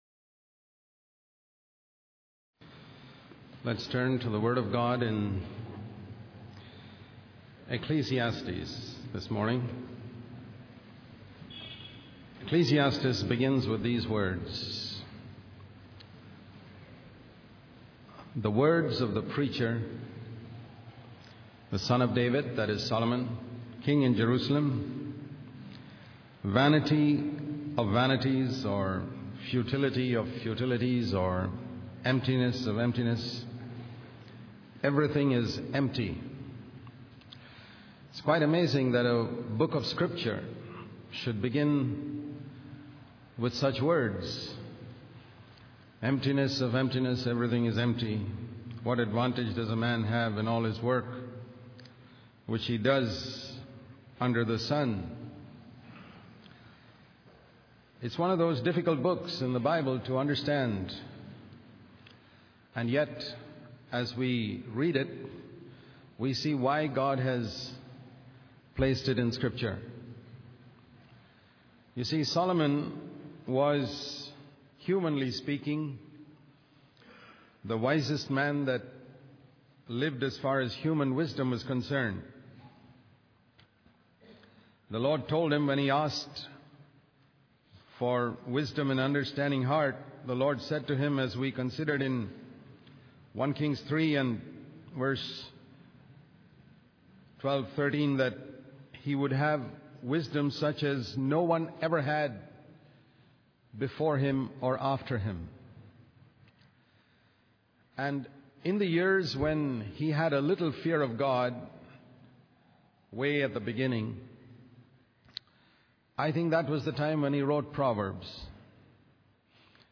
In this sermon, the preacher emphasizes the power of one word and the importance of giving generously to others. He also highlights the permanence of death and the need to serve the Lord in all circumstances.